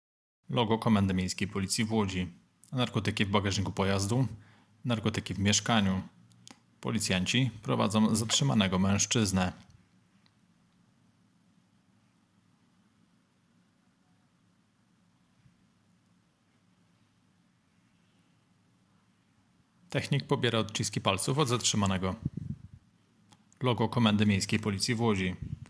Nagranie audio deskrypcja_filmu.m4a